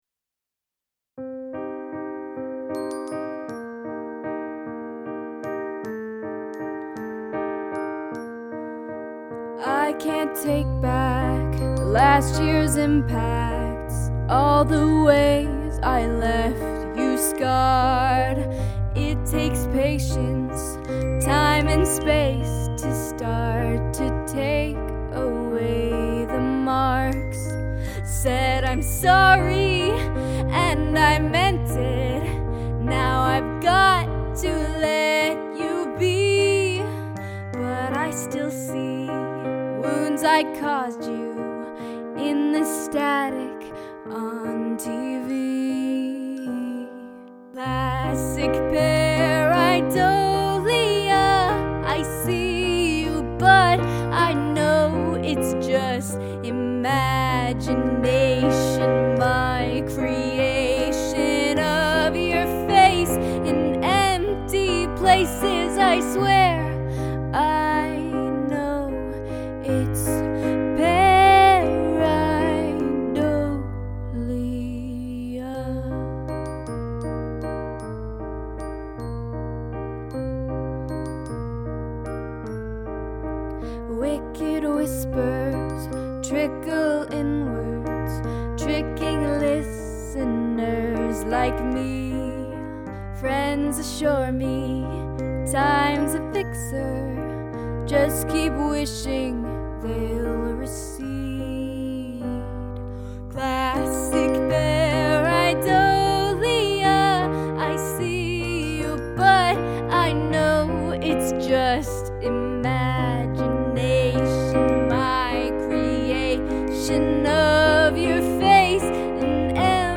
Use of field recording
Lovely voice, melody and vocal performance.